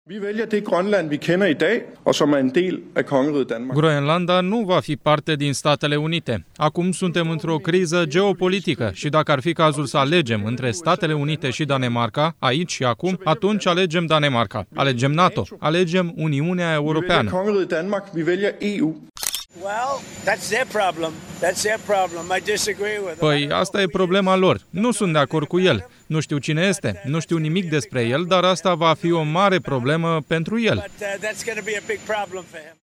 Premierul Groenlandei, Jens Frederik Nielsen: „Groenlanda nu va fi parte din Statele Unite”
Donald Trump: „Asta va fi o mare problemă pentru el”
14ian-13-Trump-Nielsen-Groenlanda-TRADUS.mp3